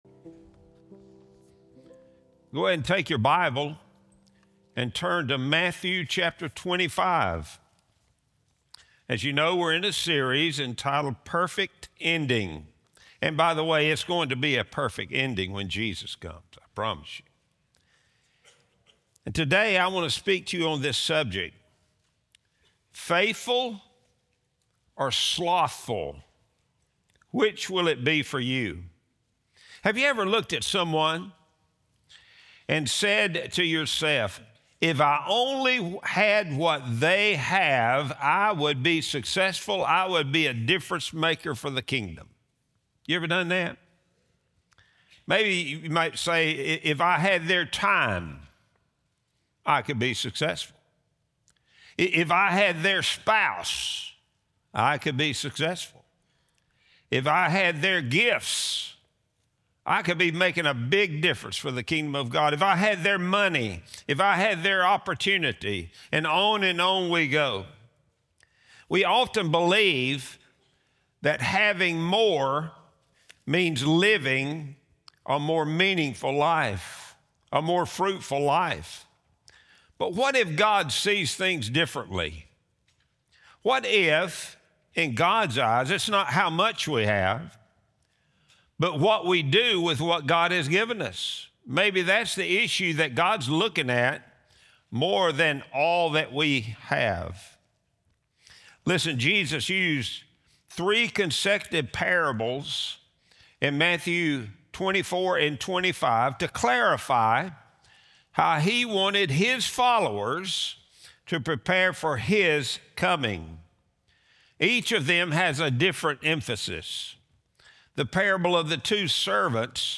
Sunday Sermon | January 18, 2026